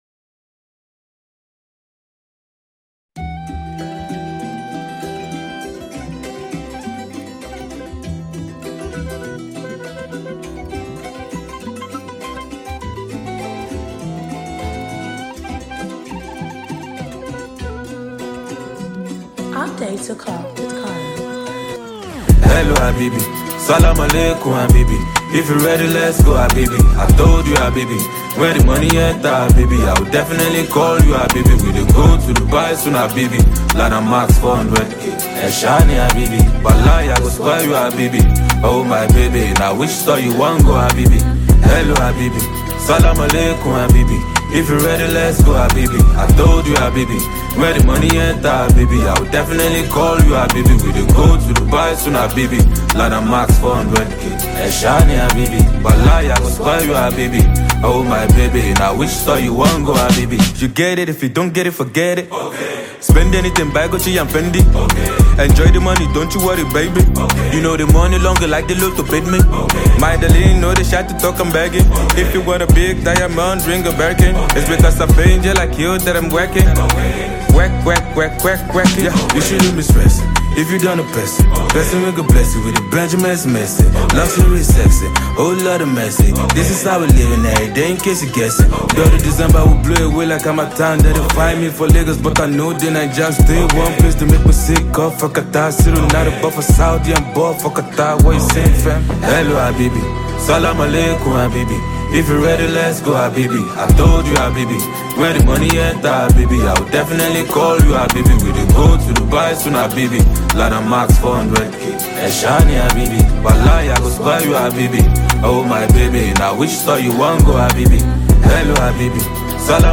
rapper
electrifying track